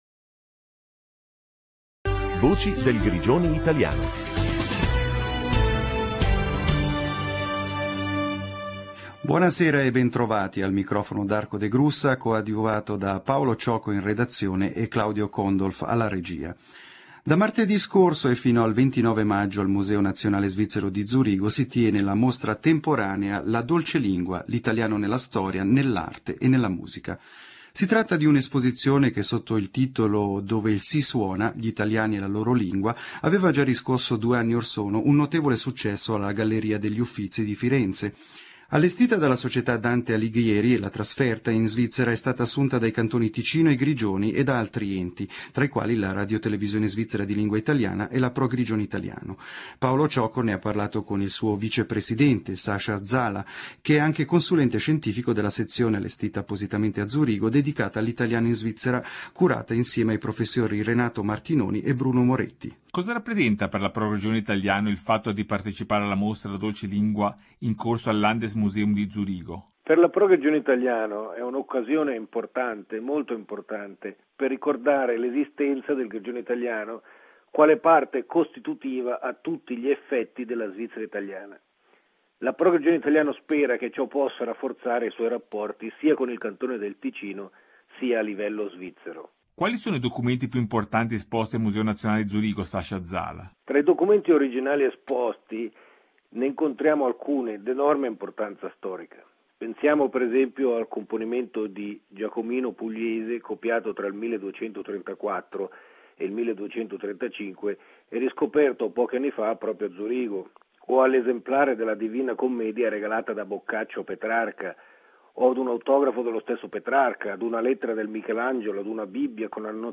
La dolce lingua. Mostra al Museo Nazionale Svizzero, 16 febbraio-29 maggio 2005, intervista